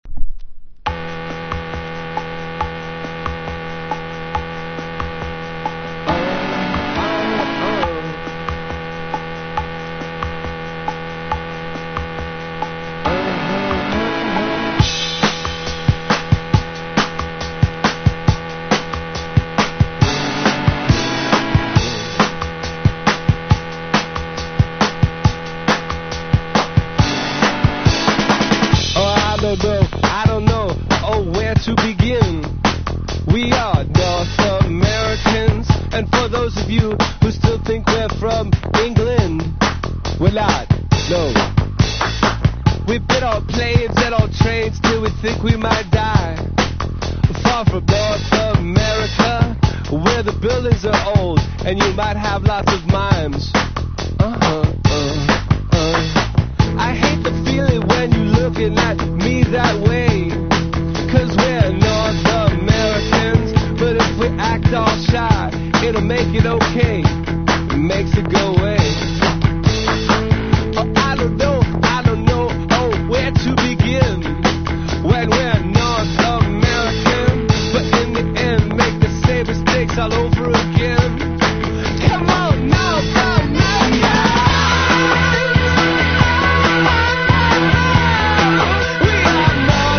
INDIE DANCE
POST PUNK (90-20’s)